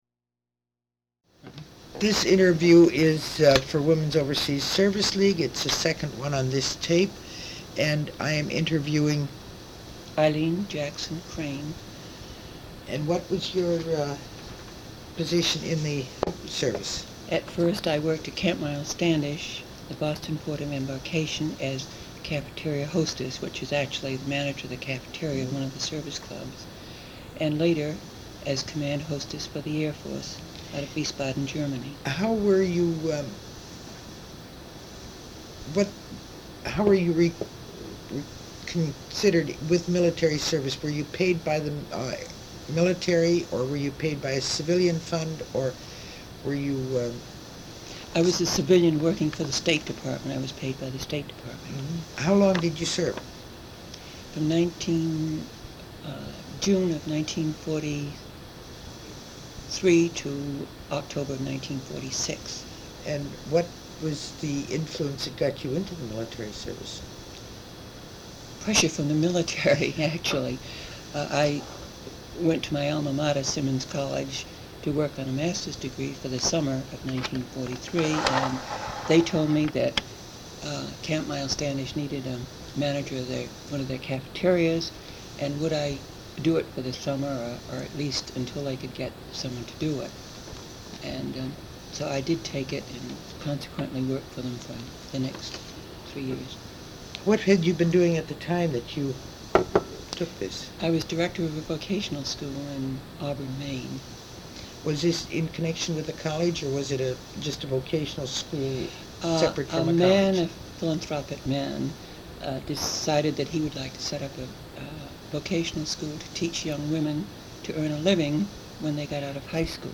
Material Type Sound recordings Interviews